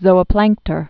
(zōə-plăngktər)